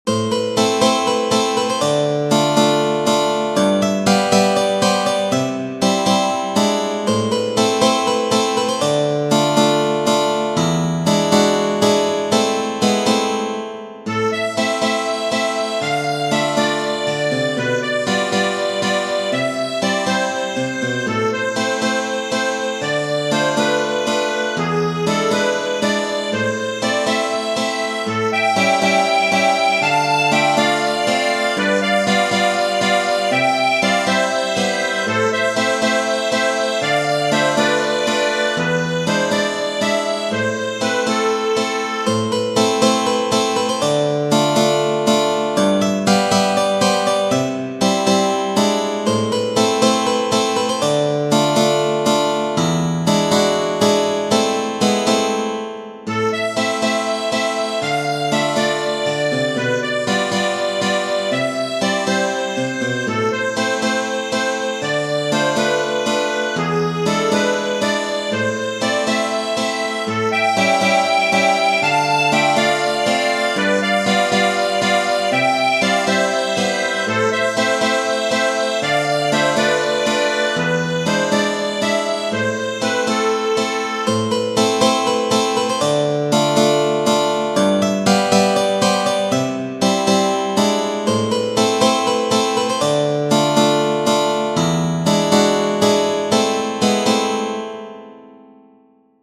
Genere: Folk